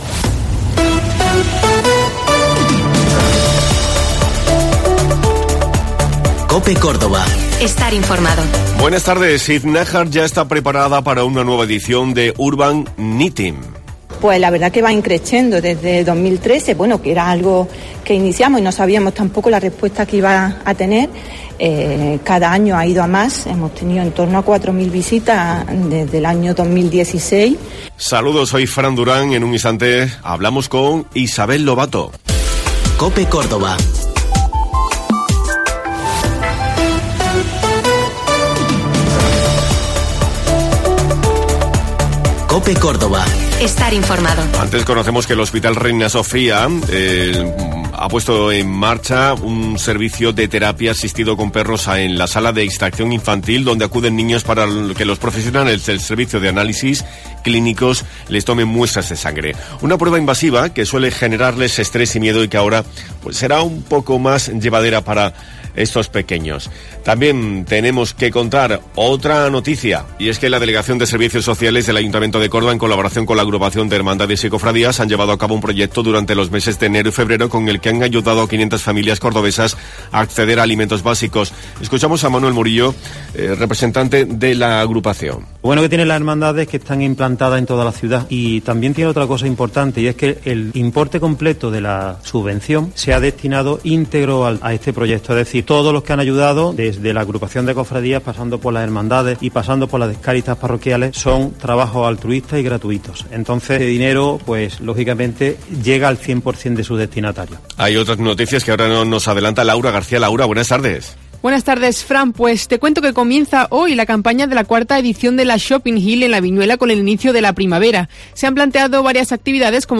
La localidad cordobesa de Iznájar lo tiene todo preparado para una nueva edición 'Urban Knitting', una iniciativa que cuenta con el apoyo de la institución provincial, a través de su Delegación de Igualdad. Isabel Lobato, concejal del Área de Igualdad y Bienestar Social en el Consistorio, nos ha contado que "nos encontramos en la octava edición de esta propuesta que en esta ocasión se denomina 'Mujeres con arte knitting', y que pretende destacar y homenajear el papel que han jugado las mujeres a lo largo de la historia del arte".